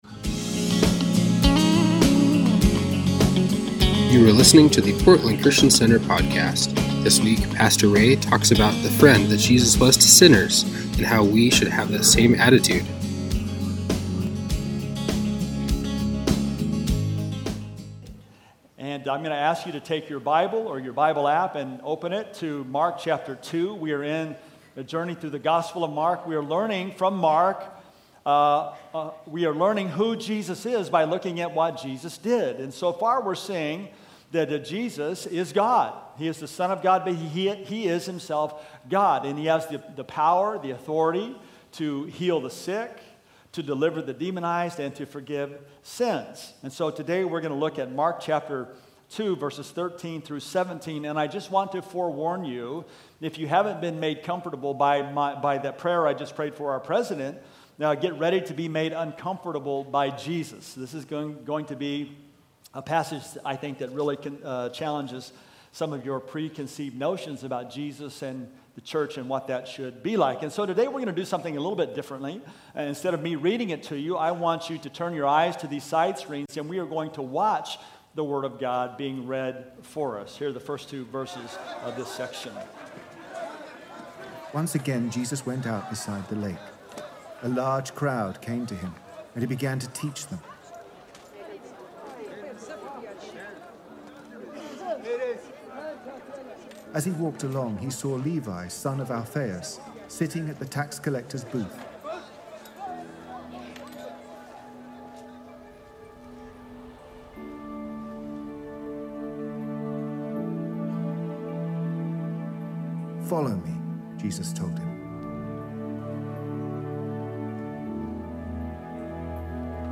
Sunday Messages from Portland Christian Center Mark 2:13-17 Jun 09 2019 | 00:17:08 1x 00:00 / 00:17:08 Subscribe Share Spotify RSS Feed Share Link Embed